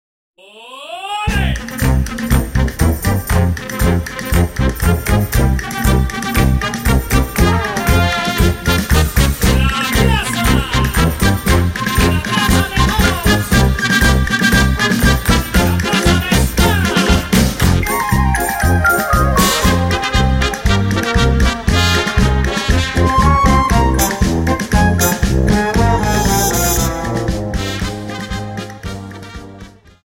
Dance: Paso Doble 60